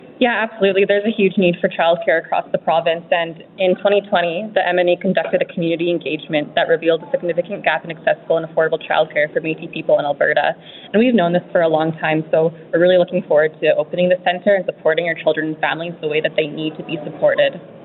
Brooke Bramfield, Secretary of Child and Family Services for the Otipemisiwak Métis Government says there is a huge need for childcare within the MNA, gathering data that showed there was a significant gap in accessible and affordable Child Care Services for Métis Peoples in Alberta.